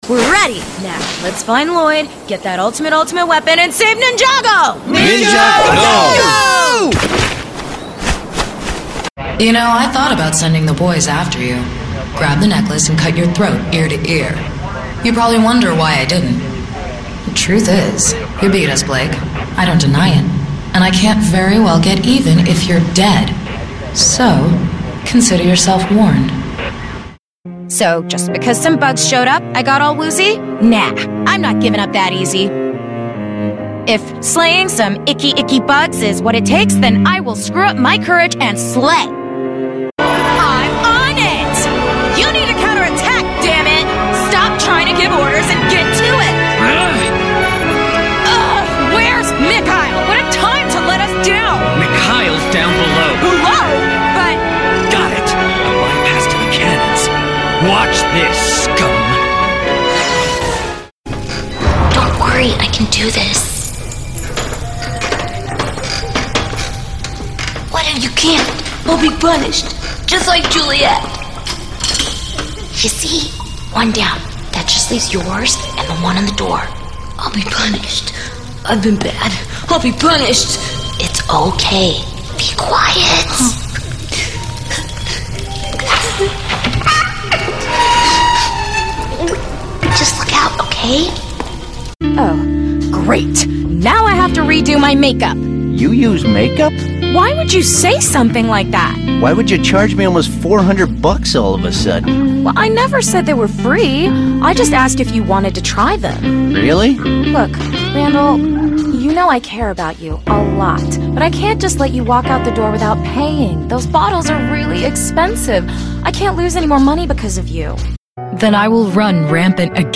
USA. Energetic, young, skilled, highly versatile.
Commercial Audio